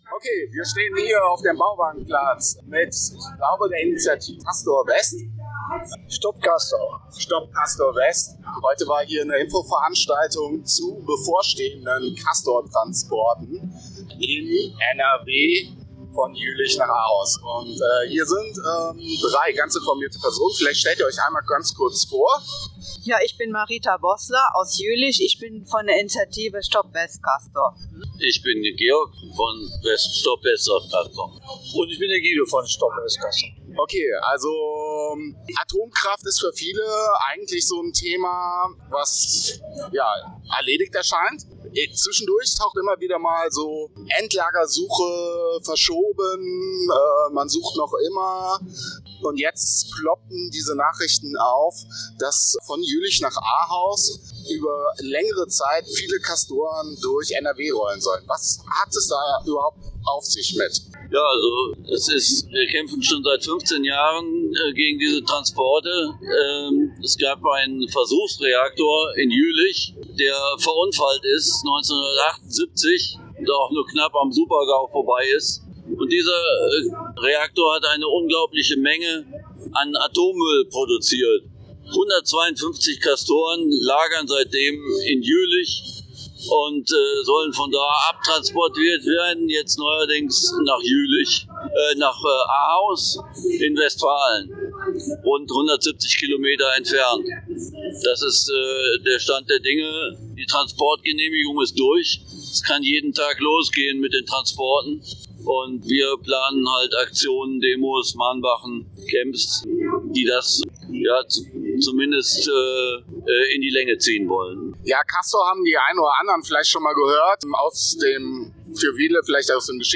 Mitschnitt der Veranstaltung vom 16.05.2025 im Petershof Köln – Die Vergangenheit ist nicht zu fern – Jugend und Strafe in der NS-Zeit und heute 25/05/2025
Moderiert wird das Gespräch vom AKJ Köln.